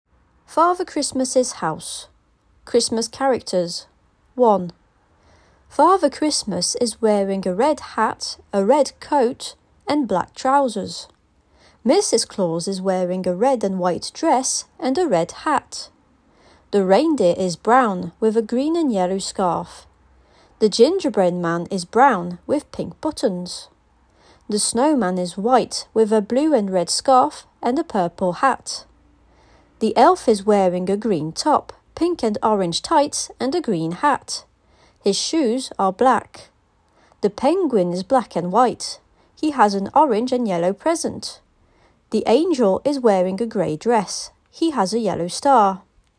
Des fichiers audio avec une voix anglaise native accompagnent l'ensemble conçu prioritairement pour le Cycle 3, mais avec des adaptations possibles pour le Cycle 2.